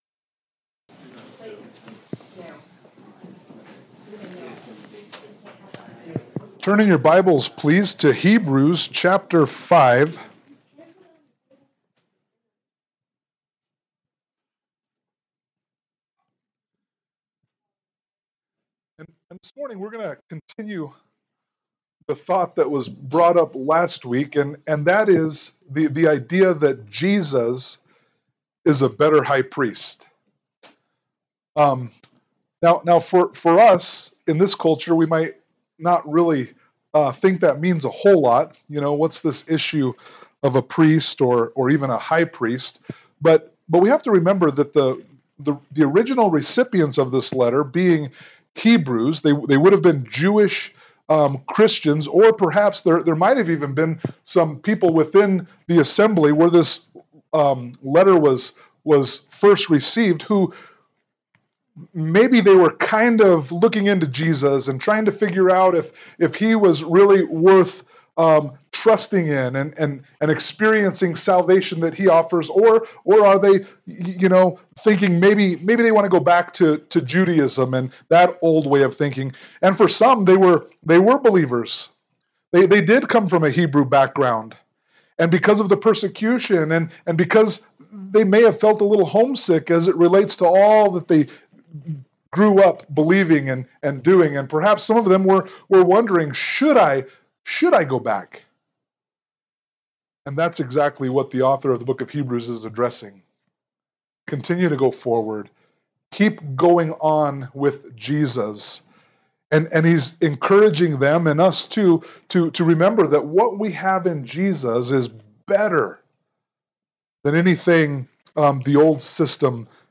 Hebrews 5:1-10 Service Type: Sunday Morning Worship « Hebrews 4:14-16 Hebrews 5:11-6:3